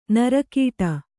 ♪ nara kīṭa